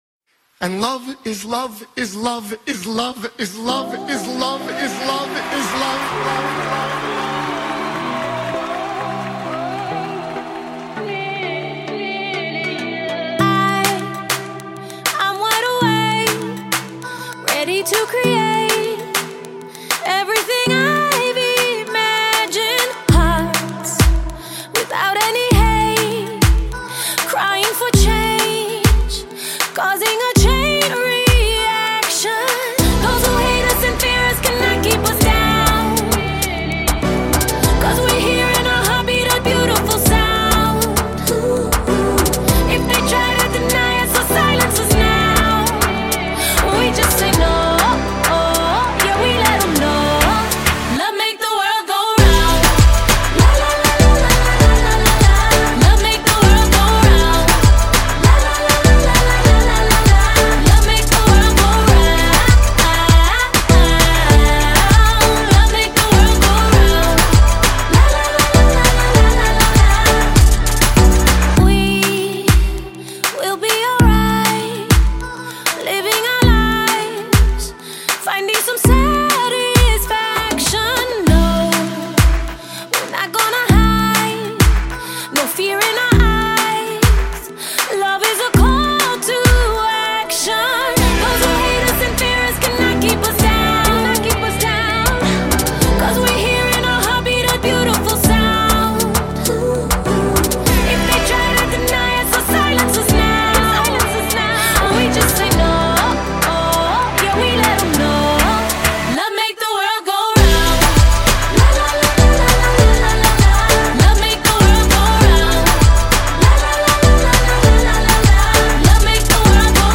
upbeat anthem